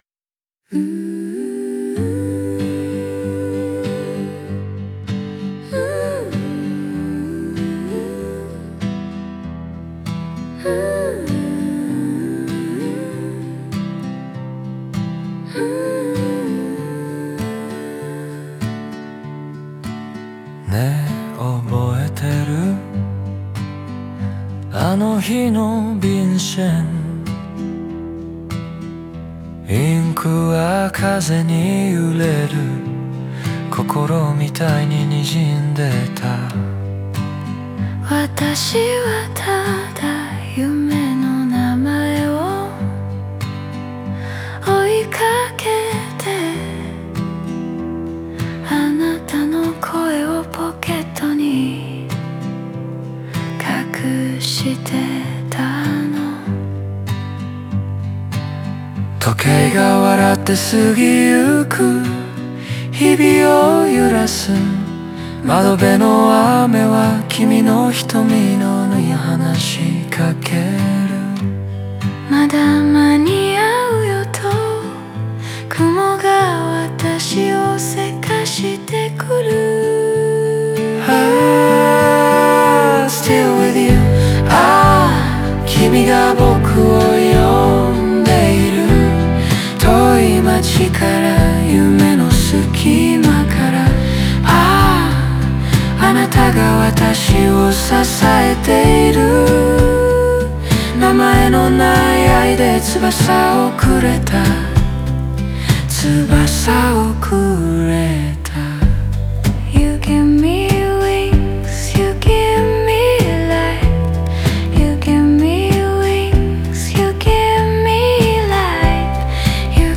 オリジナル曲♪
男と女の語りかけや独白を交互に配置し、物語性を強く持たせています。